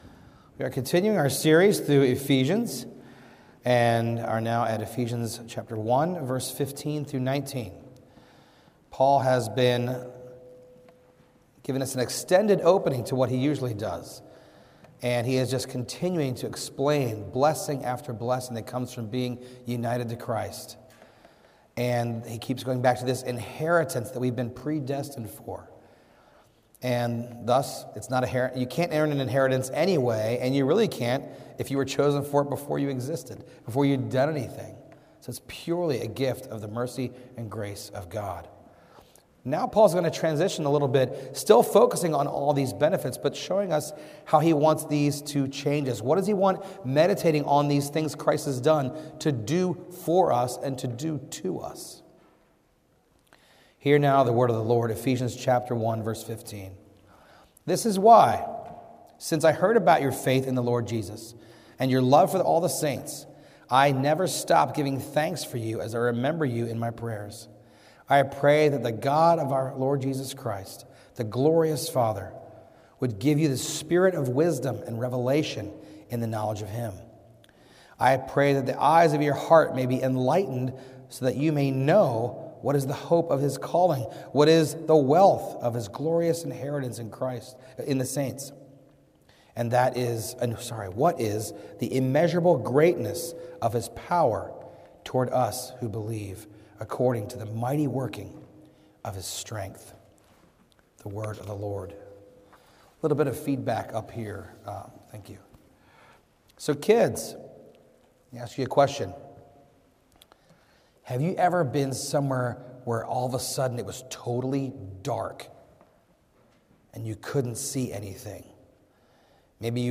A message from the series "Ephesians."